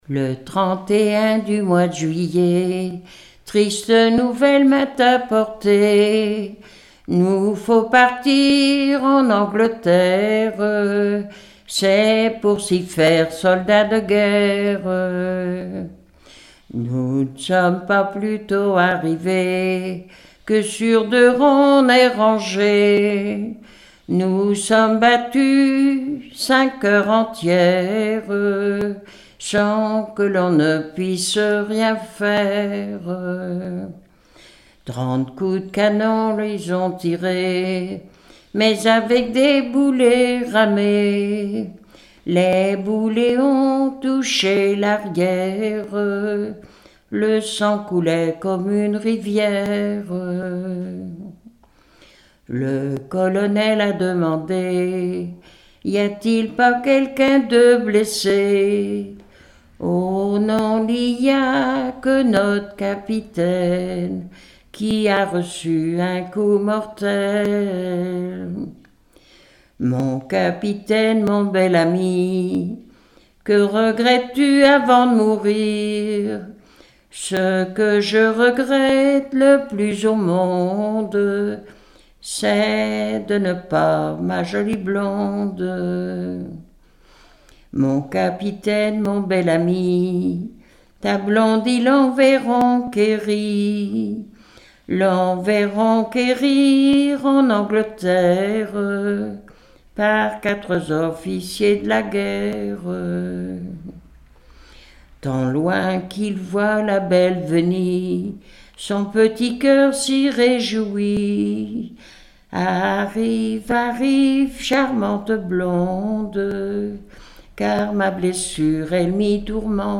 Genre strophique
Enquête Arexcpo en Vendée
Pièce musicale inédite